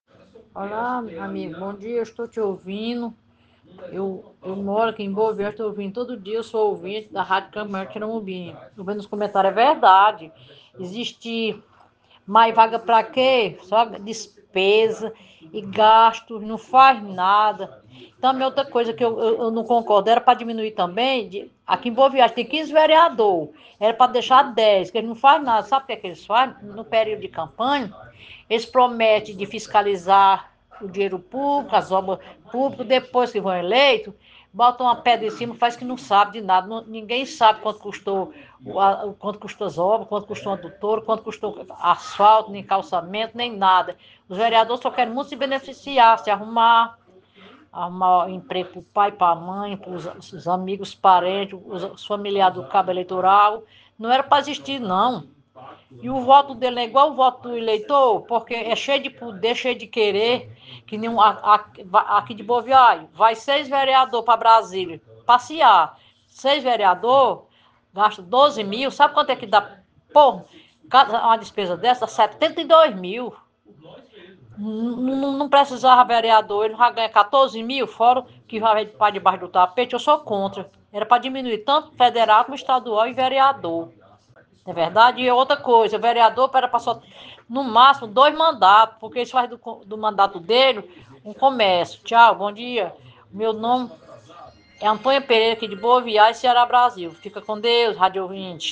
Sua declaração foi encaminhada ao programa SerTão Conta Mais, da Rádio Campo Maior AM 840, emissora integrante do Sistema Maior de Comunicação.